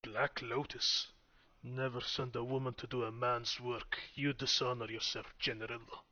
Subject description: My personal VO set   Reply with quote  Mark this post and the followings unread